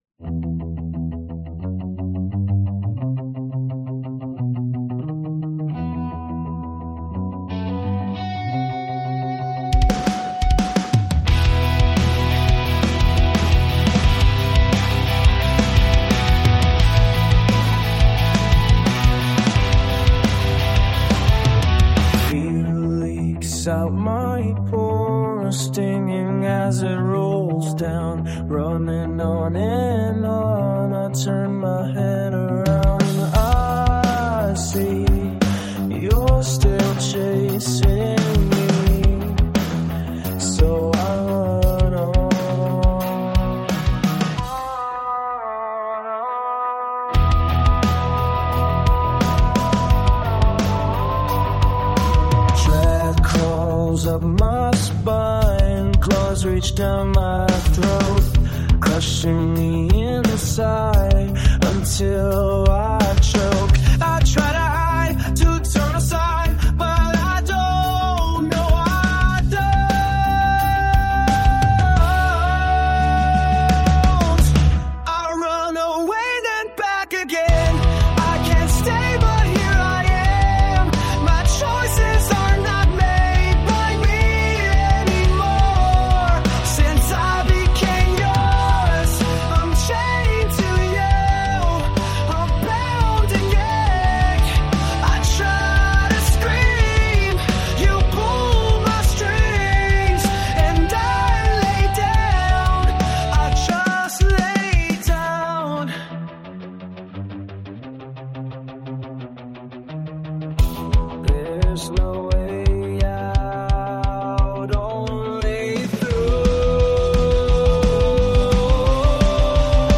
It's an app that can make musical demos of your song lyrics.